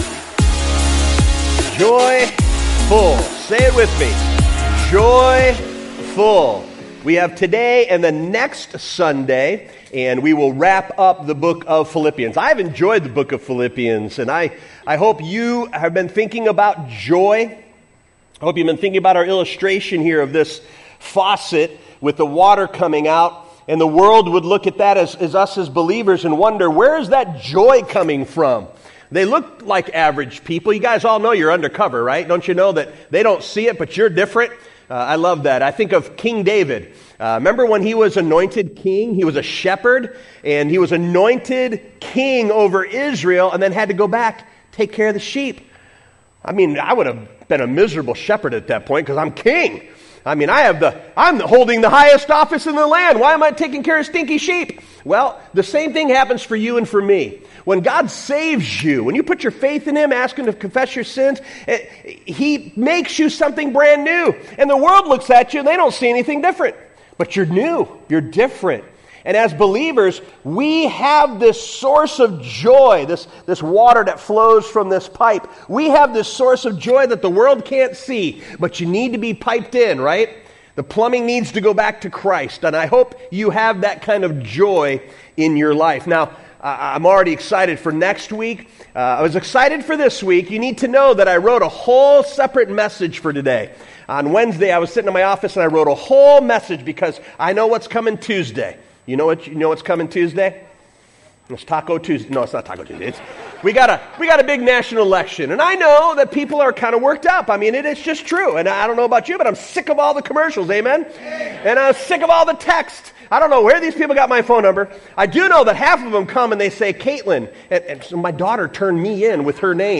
2024 Current Sermon Be An Example!